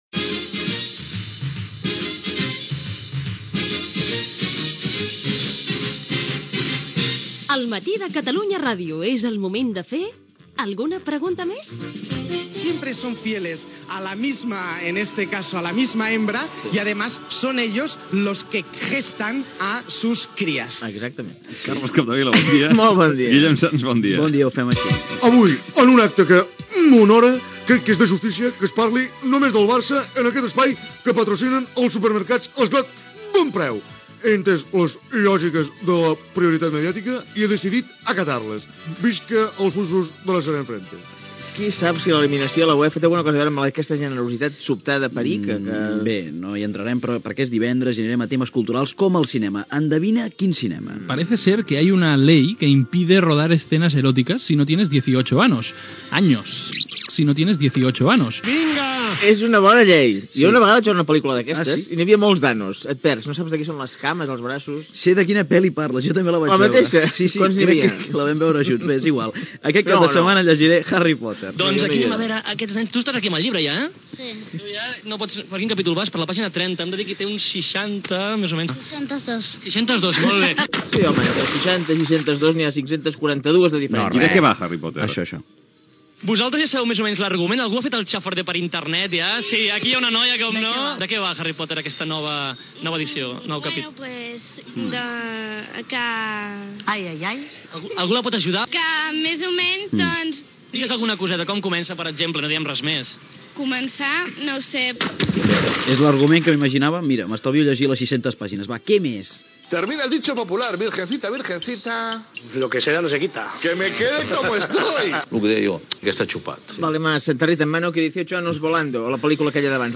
Repàs humorístic a l'actualitat, les disfresses de Carnaval, els talls finalistes de la setmana de l'APM, l'intent de cop d'estat del 23 de febrer de 1981, trucada de l'oïdor guanyador del premi Gènere radiofònic Info-entreteniment